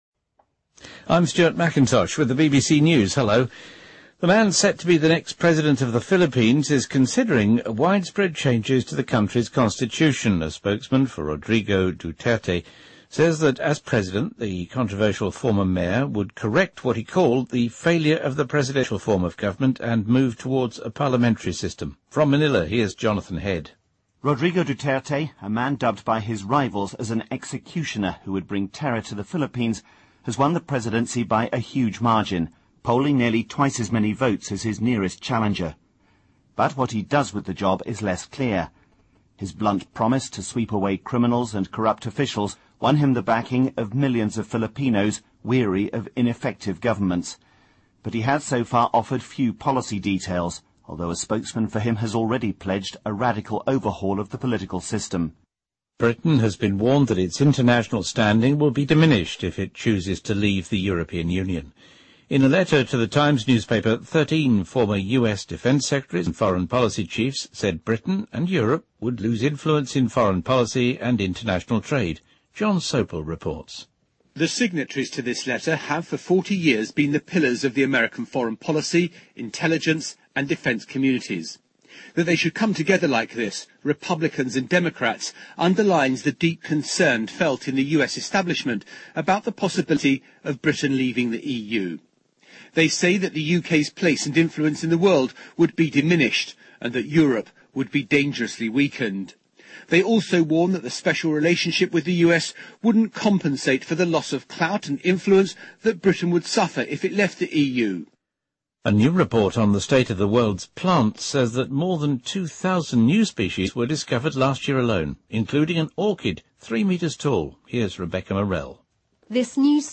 BBC news,2015年新发现了2000多种植物物种